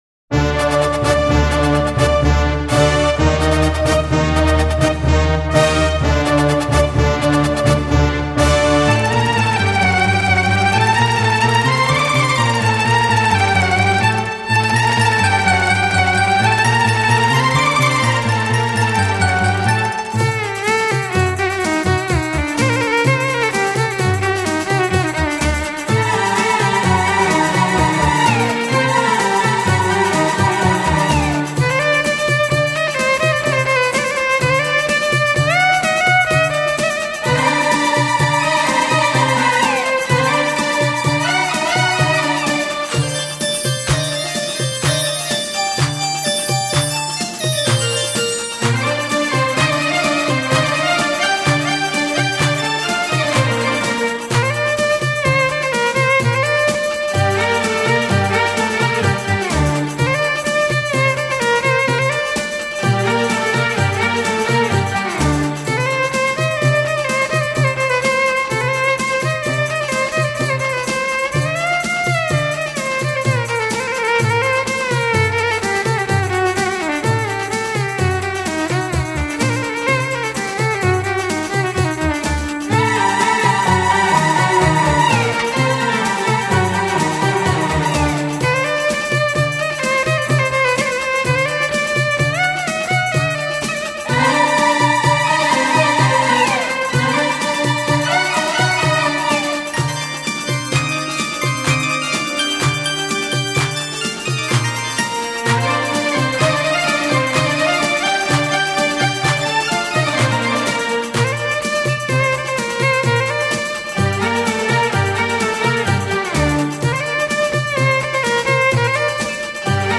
Инструментальная (без барабанов)